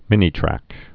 (mĭnē-trăk)